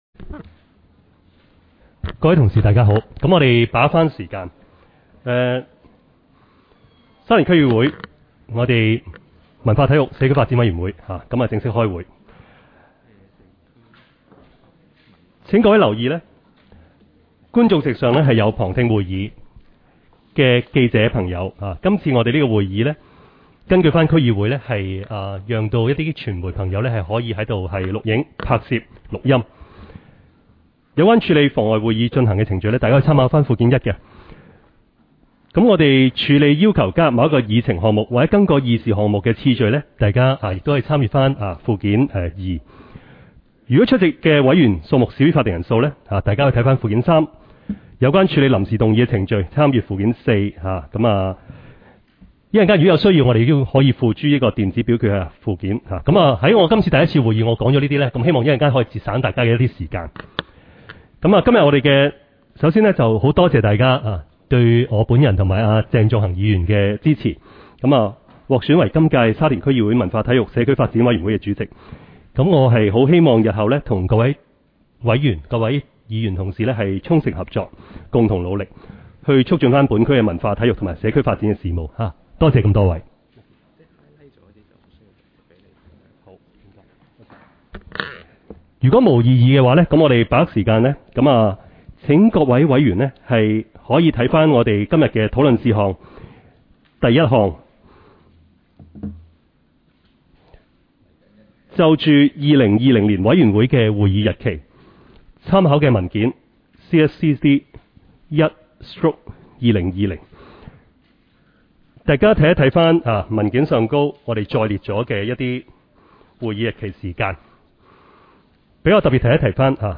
委员会会议的录音记录
地点: 沙田区议会会议室